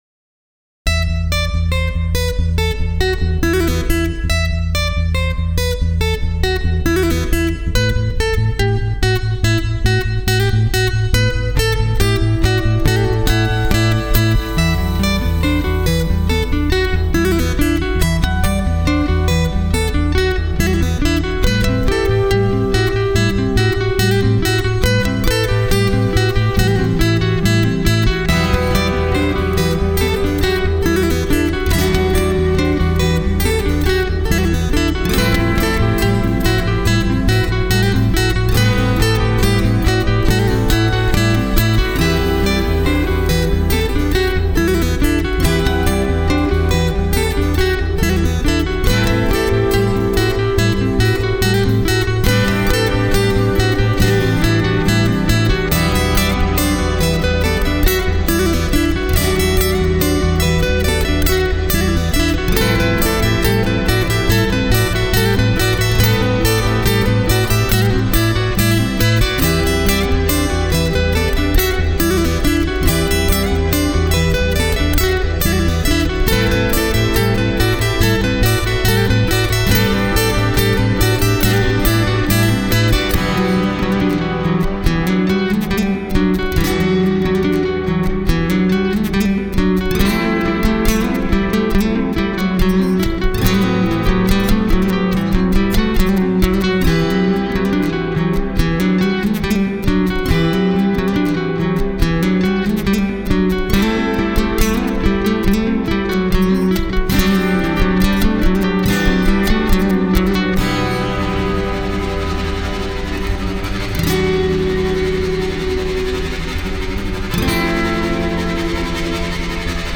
Genre:Cinematic
高級スチール弦アコースティックギターで演奏されているため、すべてのサンプルで最高品質のサウンドが期待できます。
10 Acoustic Guitar Song Kits
24 Bowed Acoustic Guitar Loops